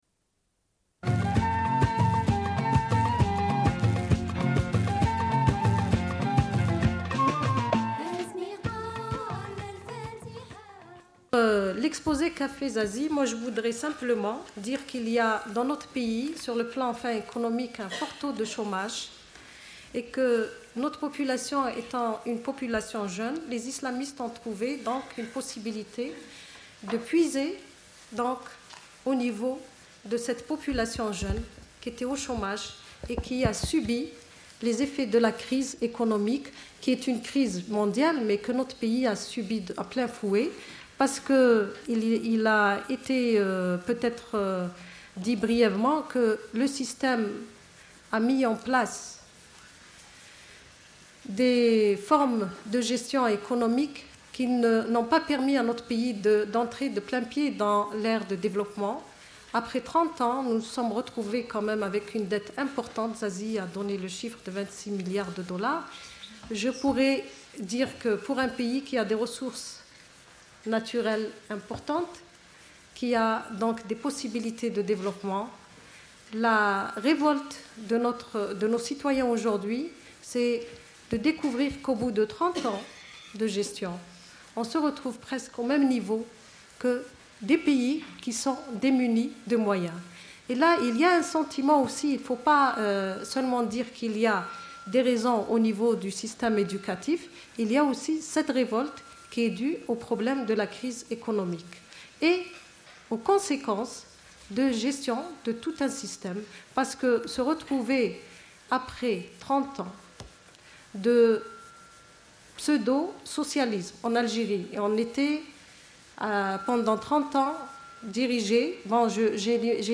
Une cassette audio, face A31:00
Suite de l'émission : diffusion d'un séminaire du 15 juin 1995 organisé par EFI, le Collectif du 14 juin et d'autres groupes féministes genevois à l'occasion d'une semaine de solidarité avec les femmes algériennes autour du 14 juin.